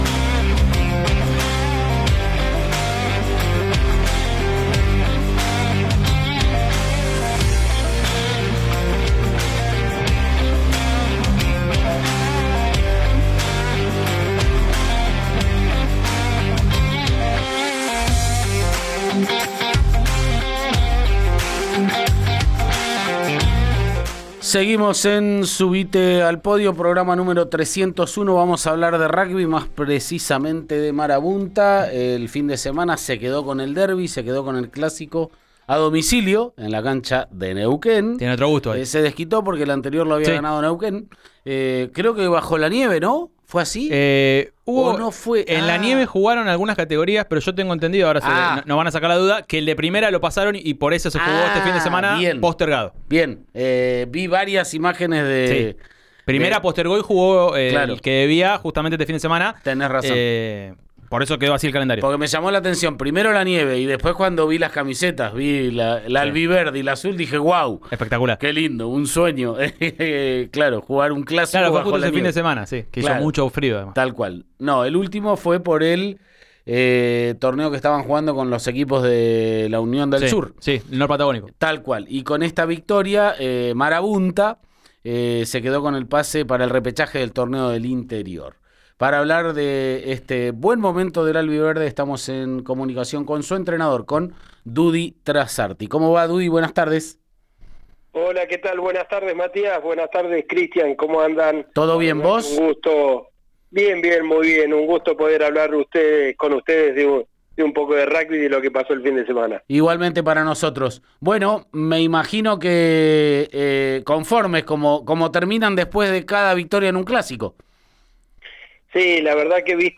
en diálogo con «Subite al Podio» de Río Negro Radio